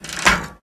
crank_craft_lever_01.ogg